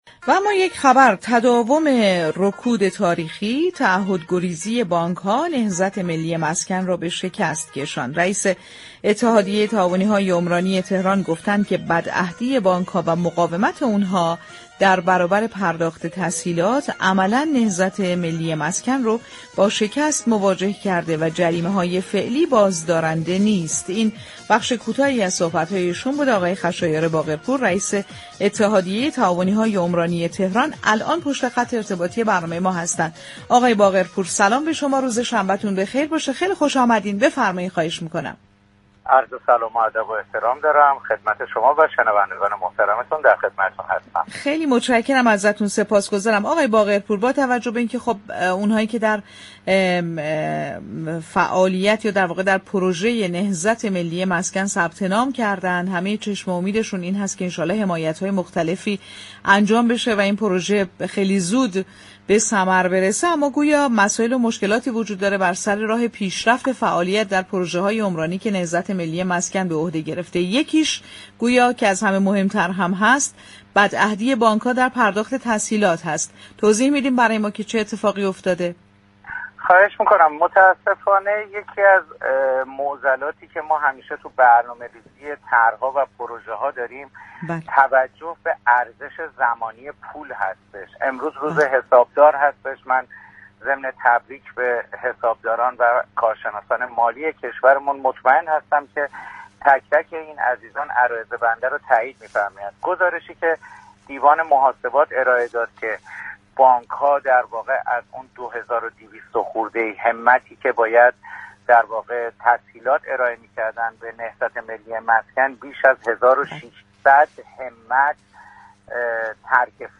در گفت‌وگو با رادیو تهران